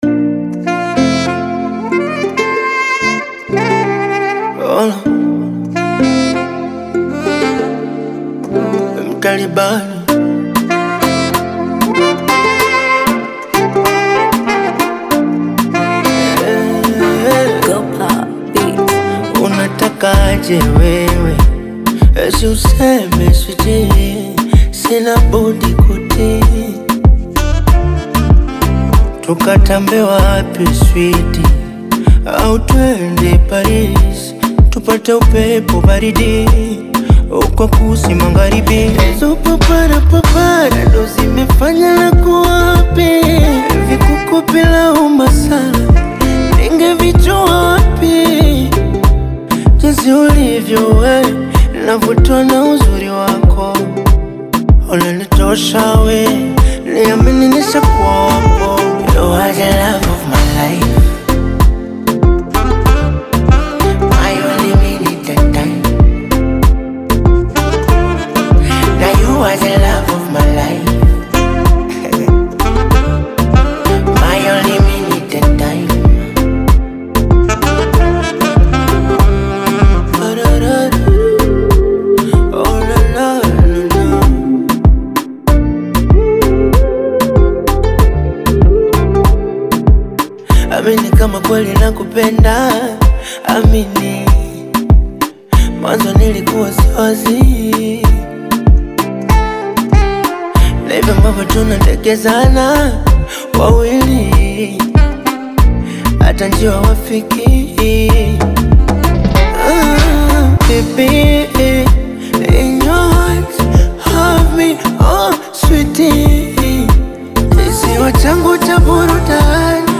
Bongo Flava
Tanzanian Bongo Flava artist, singer, and songwriter